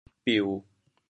调: 低 国际音标 [piu]
biu1.mp3